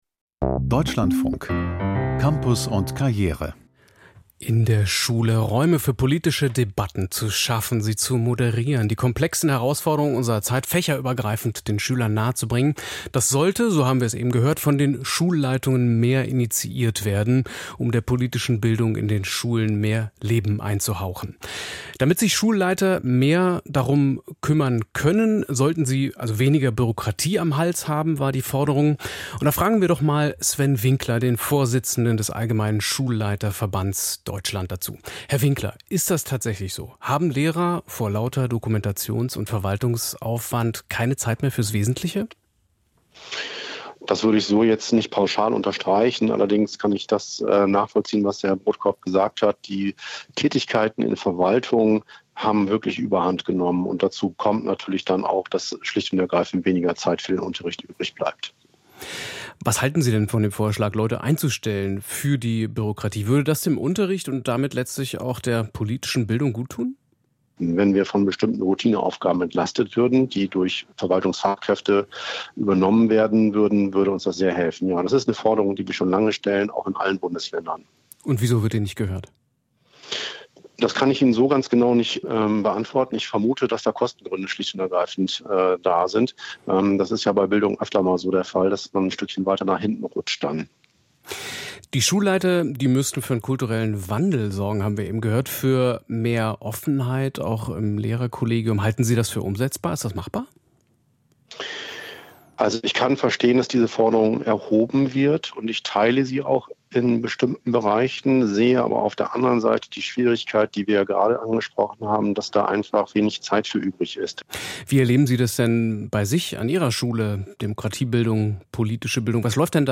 Demokratiebildung: Mehr Beinfreiheit für Schulleiter? - Interview